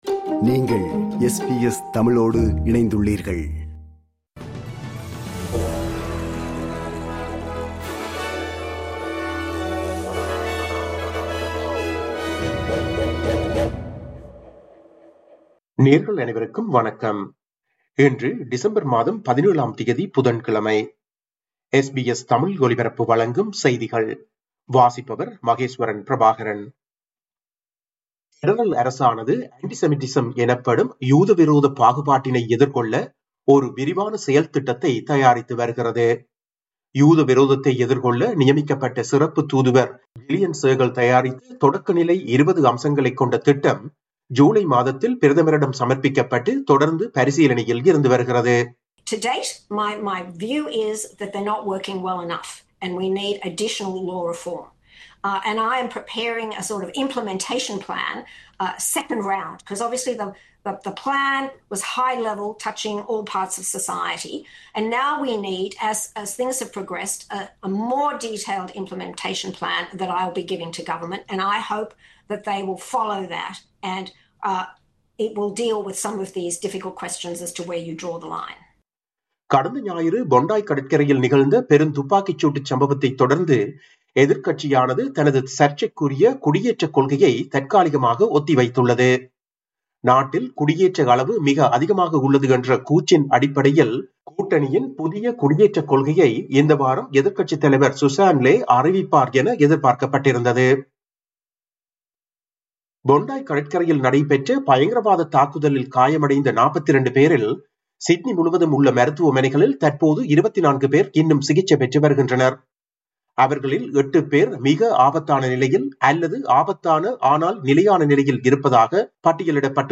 SBS தமிழ் ஒலிபரப்பின் இன்றைய (புதன்கிழமை 17/12/2025) செய்திகள்.